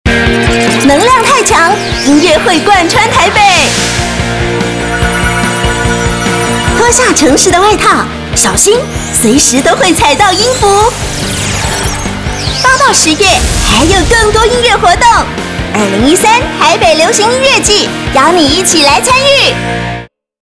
台語配音 國語配音 女性配音員 客語配音
(活力 國)台北流行音樂季
她亦擅長年輕女性、母性角色及莊重旁白語調，廣受廣播劇與政府標案製作青睞。
活力-國台北流行音樂季.mp3